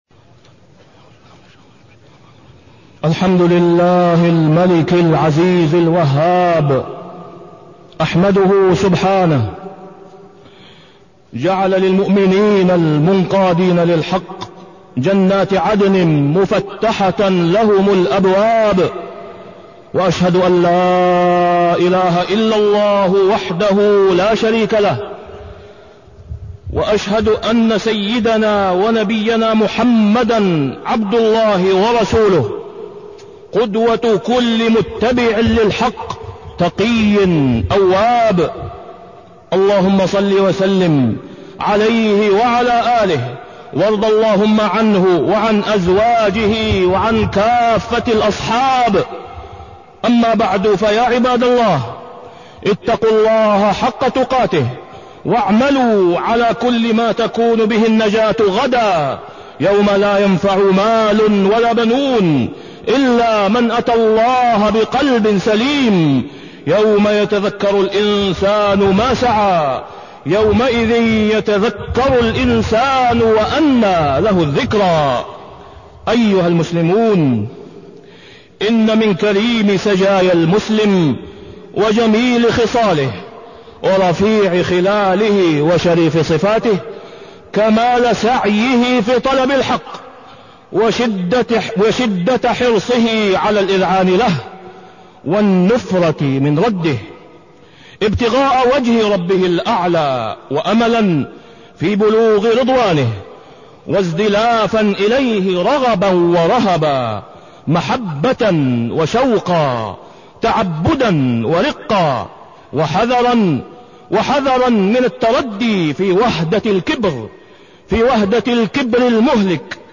تاريخ النشر ١٠ ذو القعدة ١٤٢٤ هـ المكان: المسجد الحرام الشيخ: فضيلة الشيخ د. أسامة بن عبدالله خياط فضيلة الشيخ د. أسامة بن عبدالله خياط الكبر